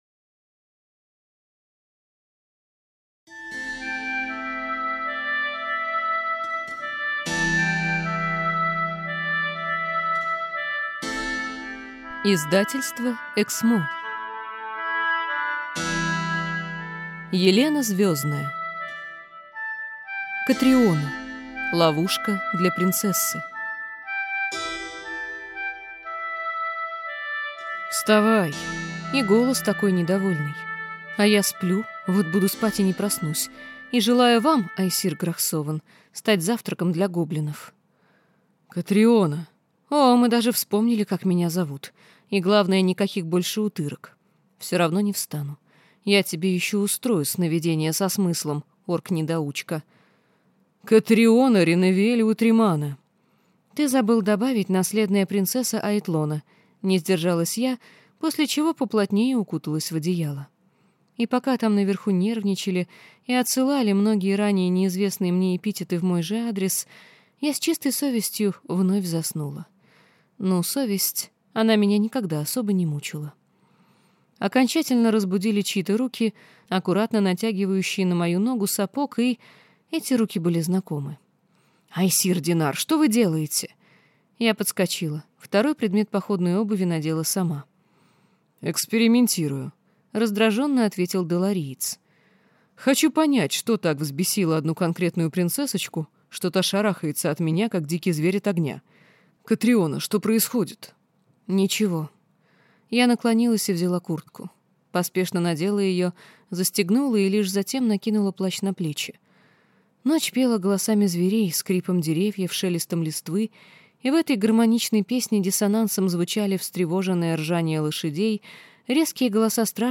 Аудиокнига Ловушка для принцессы | Библиотека аудиокниг